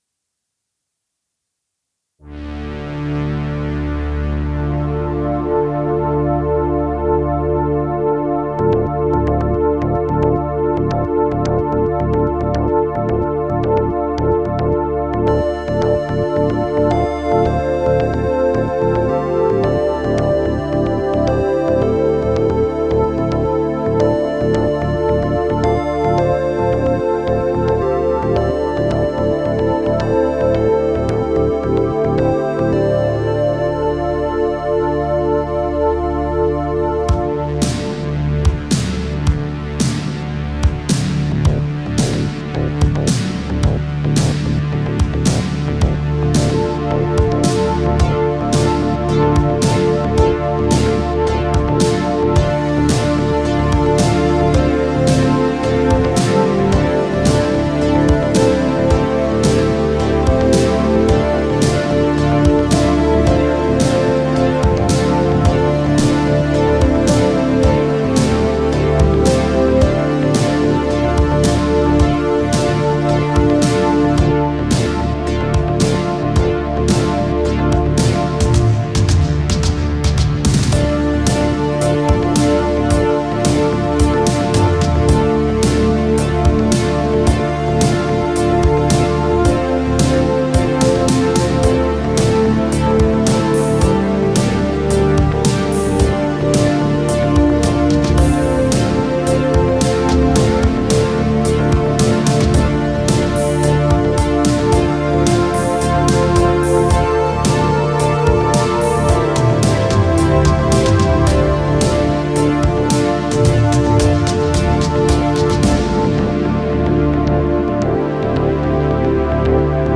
This also very synth heavy, to give the video game sci-fi vibe he wanted. (This one has no guitars at all...)
Again, lots of synths involved! u-he's Diva was used for bass and pads, while the main theme lead synths were done with ZynAddSubFX.
I started with the intention of making it more electronic sounding.